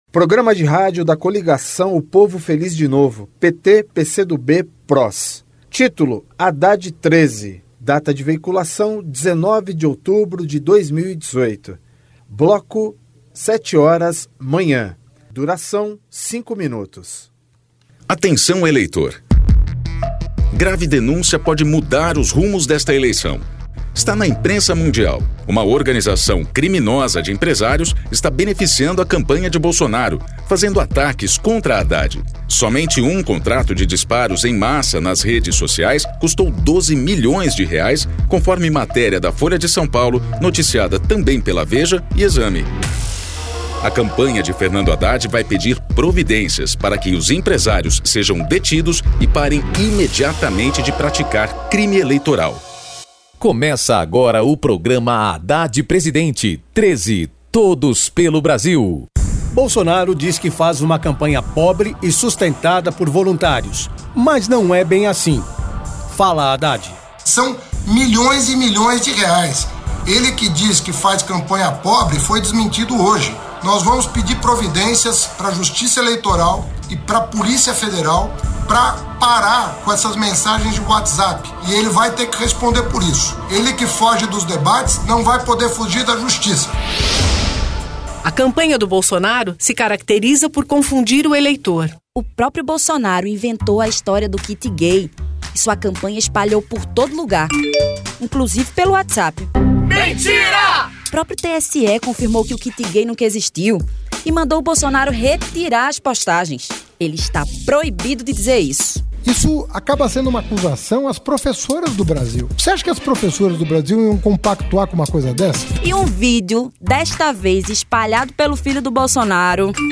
TítuloPrograma de rádio da campanha de 2018 (edição 43)
Descrição Programa de rádio da campanha de 2018 (edição 43), 2º Turno, 19/10/2018, bloco 7hrs.